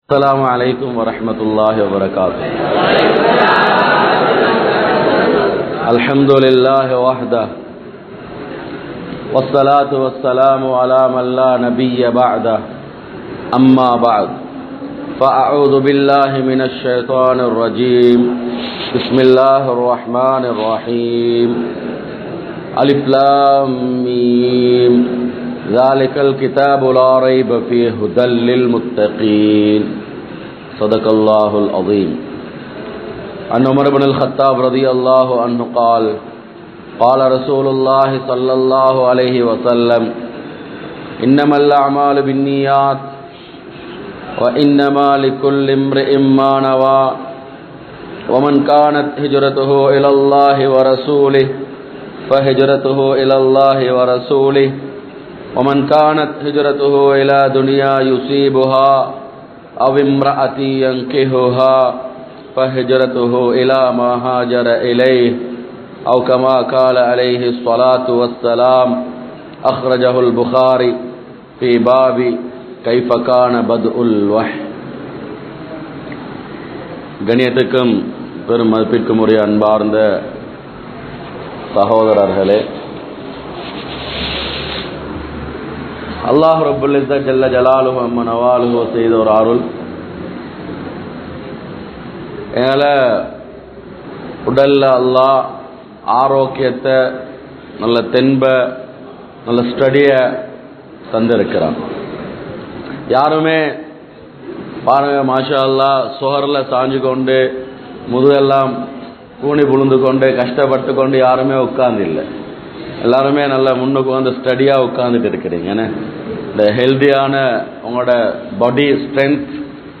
Mun Maathiriyaana Vaalifarhal (முன்மாதிரியான வாலிபர்கள்) | Audio Bayans | All Ceylon Muslim Youth Community | Addalaichenai